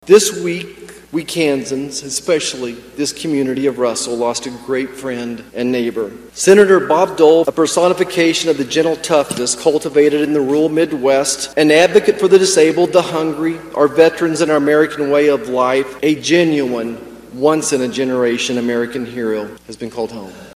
Senator Roger Marshall in his speech says Dole never lost sight of his values that were forged living in Russell.